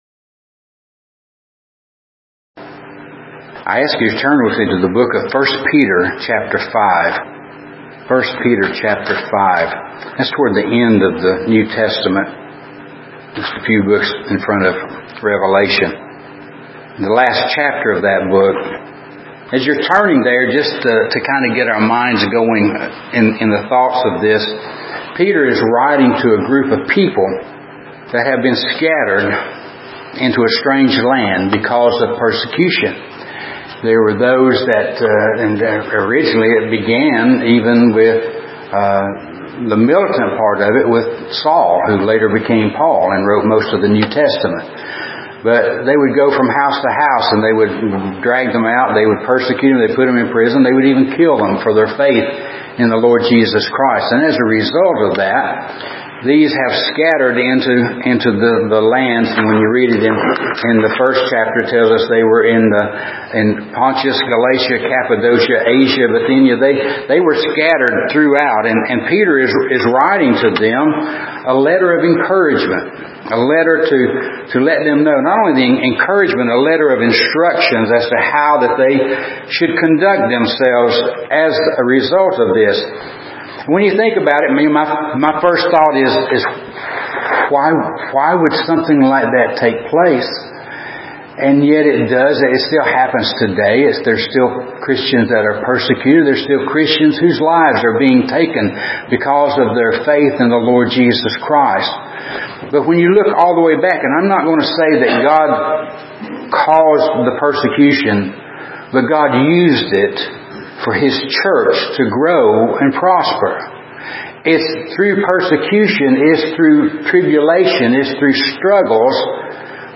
Sermon by Speaker